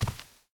sounds / step / cloth1.ogg
cloth1.ogg